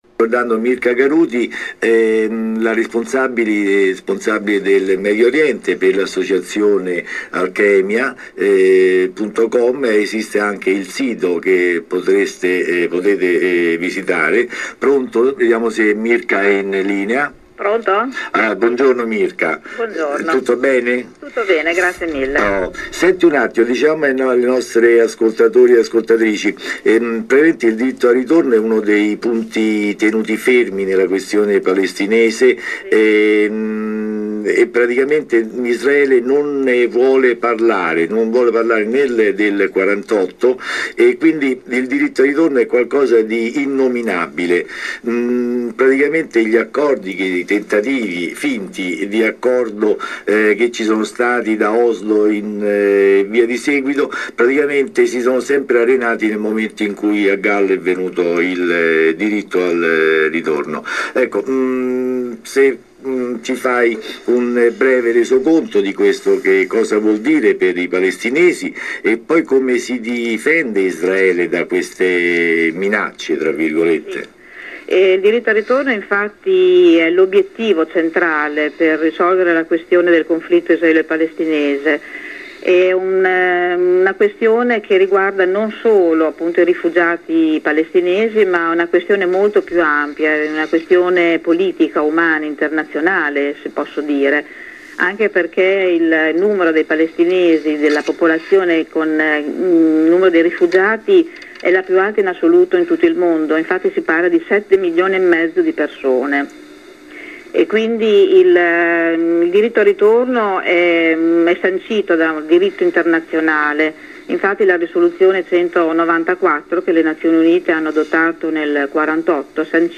Diritto al ritorno: interv ad Alkemia